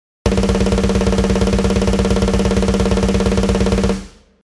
ブラヴィティブラスト（スネアを倍叩く）
某フーロなんとかさんで有名になった、スネア叩きまくりのパターンです。
ブラストビートのサンプル4
• ブラヴィティブラスト＝テクニカルな雰囲気